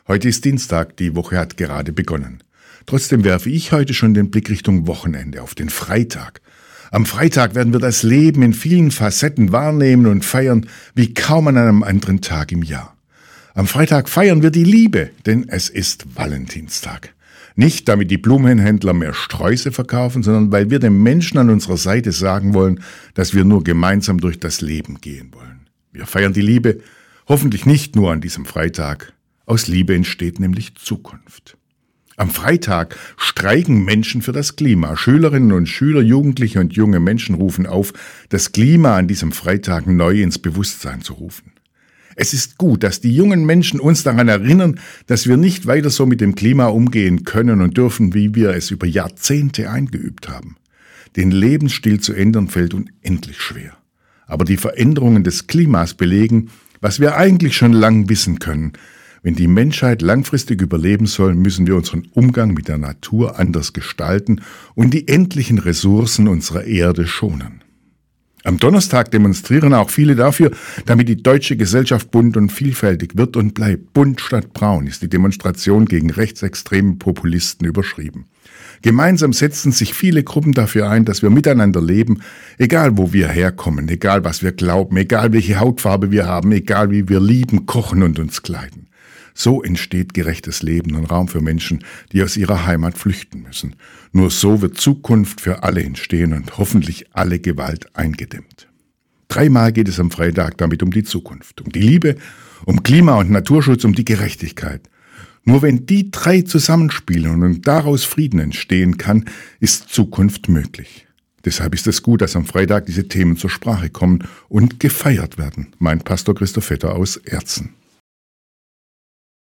Radioandacht vom 11. Februar